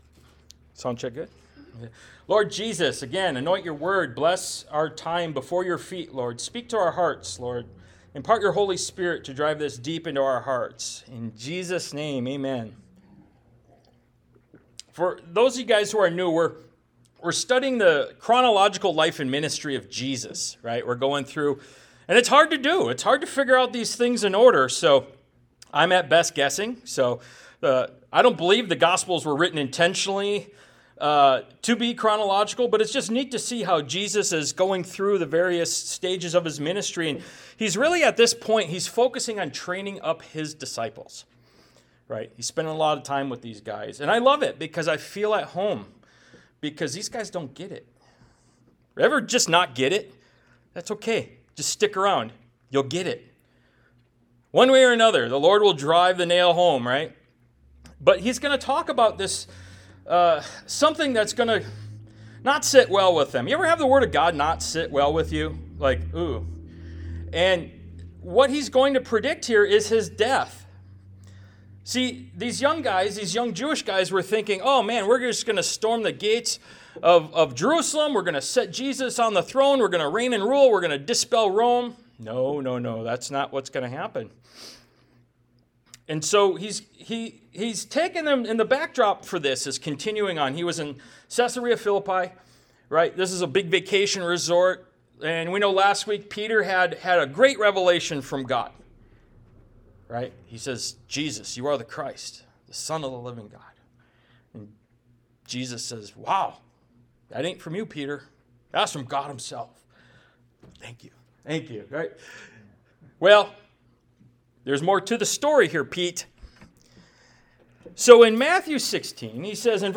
Ministry of Jesus Service Type: Sunday Morning « “Who Do You Say I Am?”